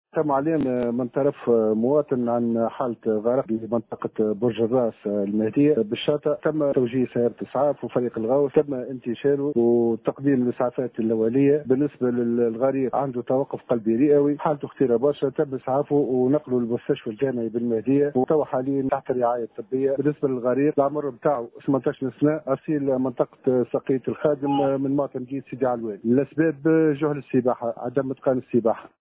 في تصريح ل “ام اف ام”